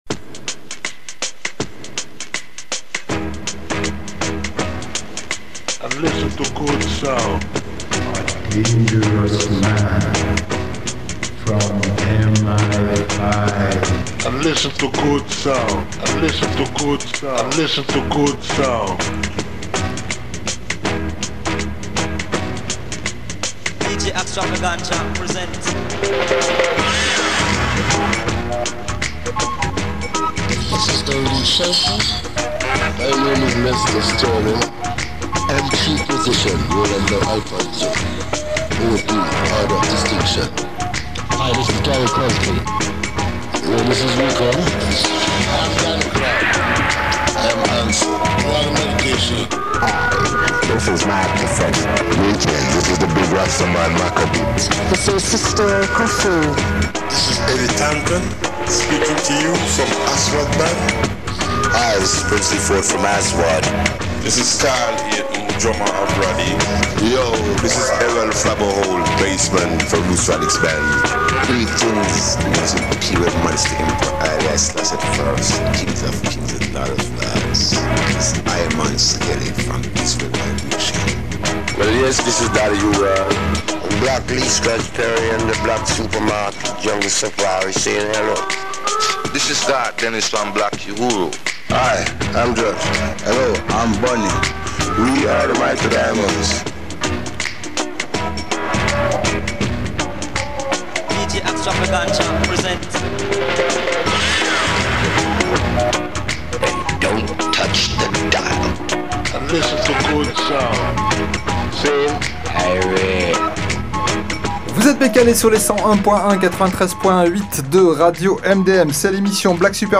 radio show !